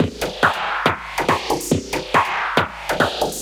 tx_perc_140_queasy.wav